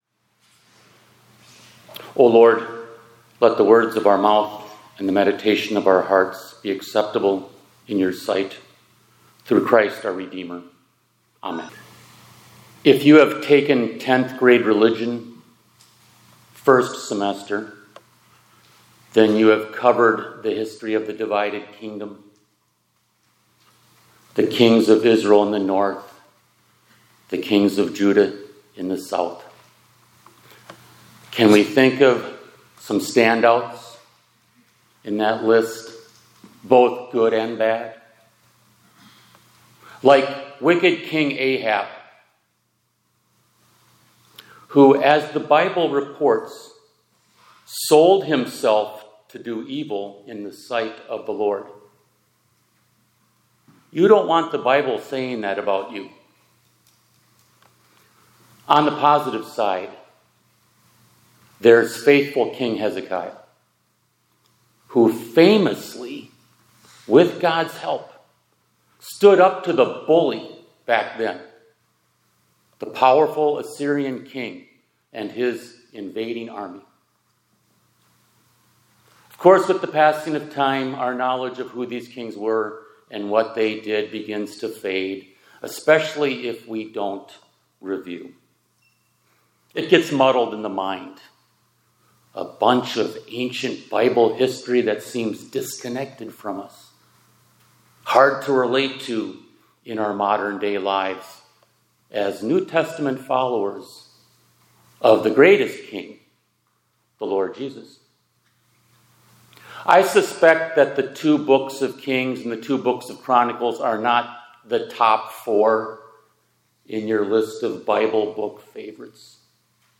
2026-01-16 ILC Chapel — Patterns That Repeat